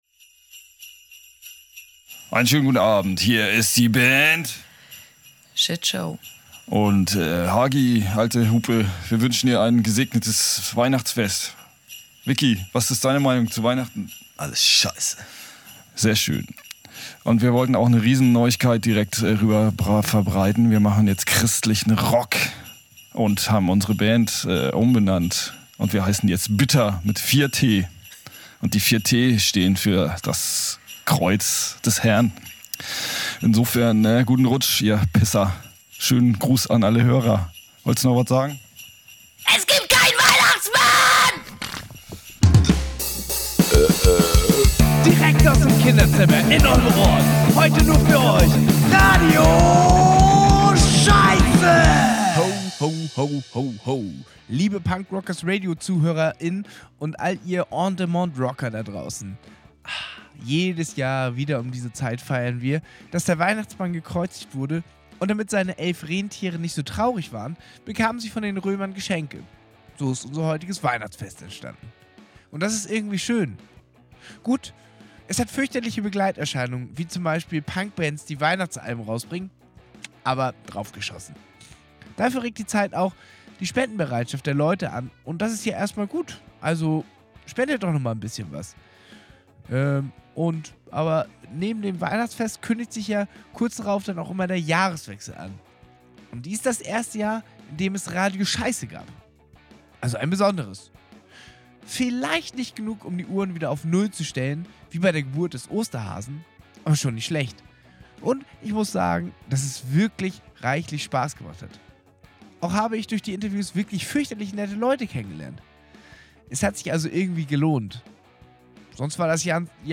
Punkrock Audio-Fanzine